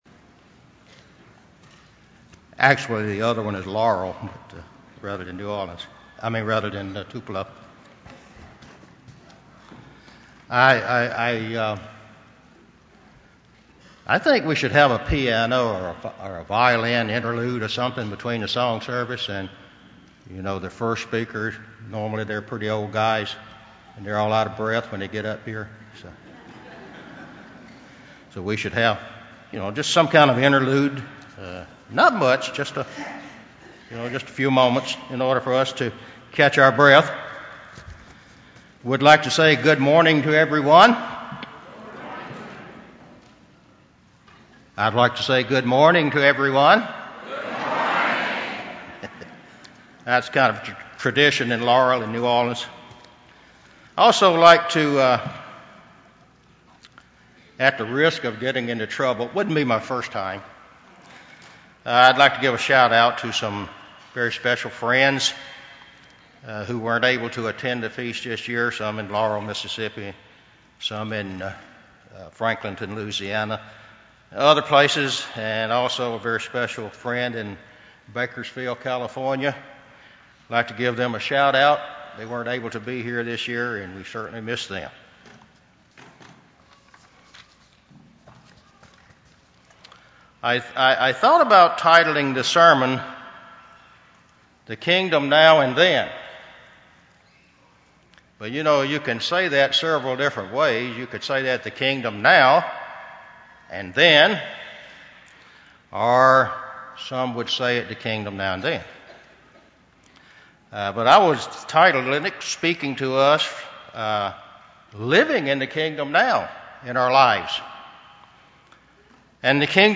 This sermon was given at the Panama City Beach, Florida 2016 Feast site.